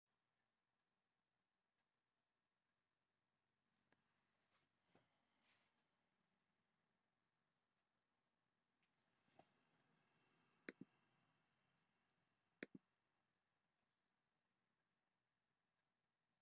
Prime Jive: Monday Afternoon Show- Live from Housatonic, MA (Audio)